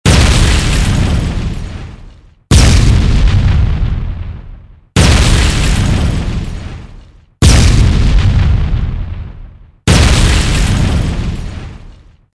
siege_door.mp3